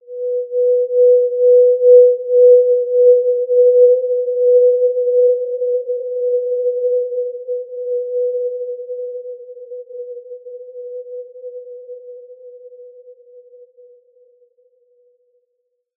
Basic-Tone-B4-mf.wav